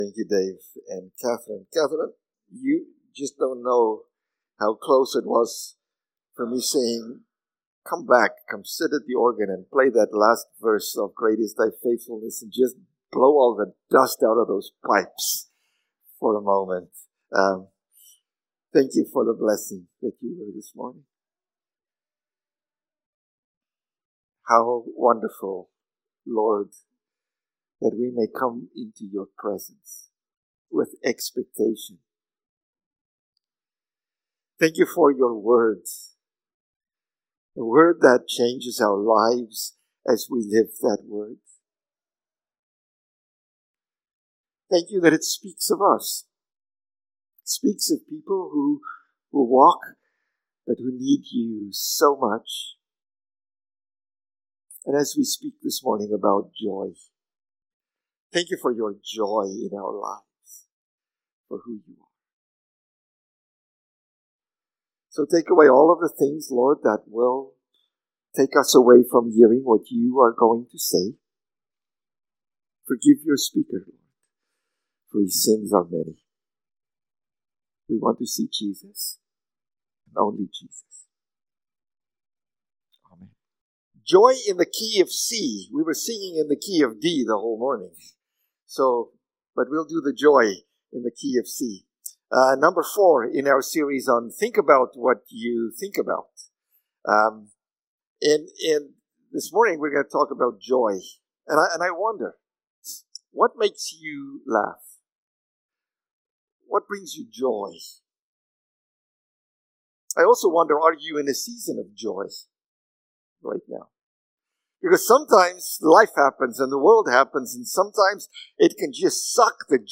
June-15-Sermon.mp3